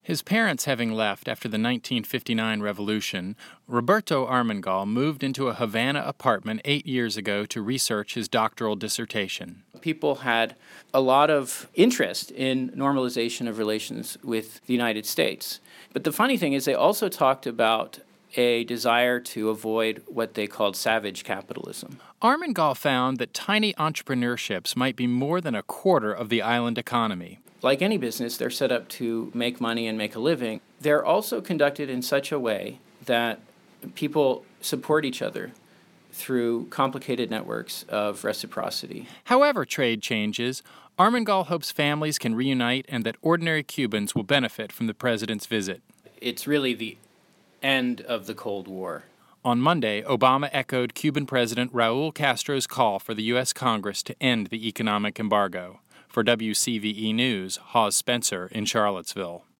For WCVE News